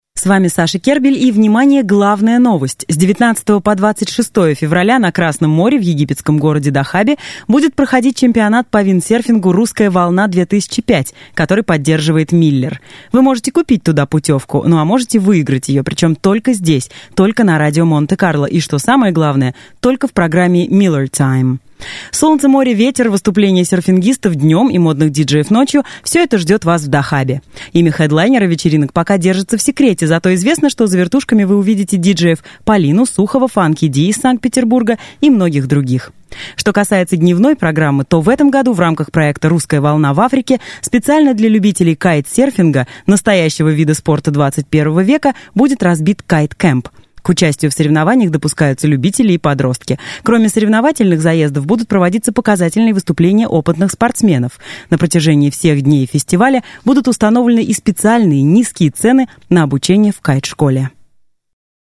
Sprecherin russisch für TV / Rundfunk / Industrie.
Sprechprobe: Werbung (Muttersprache):
Professional female russian voice over artist.